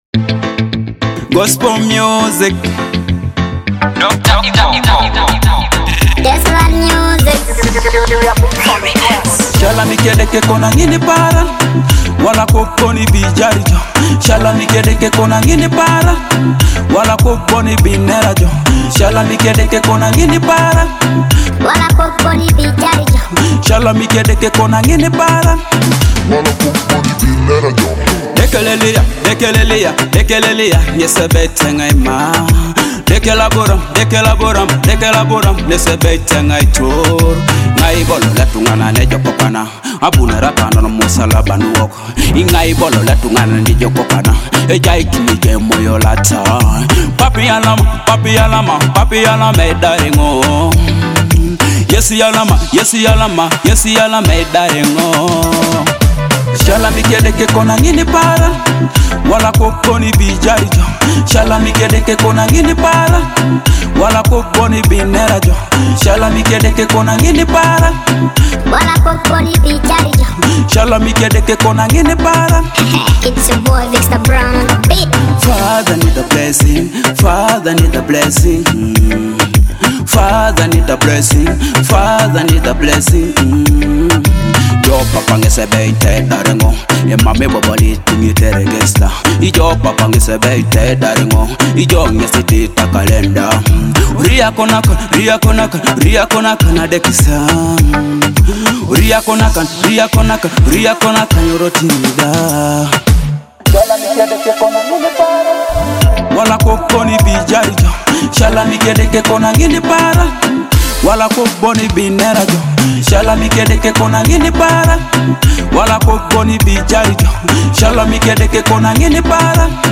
feel the uplifting spirit of this must-have anthem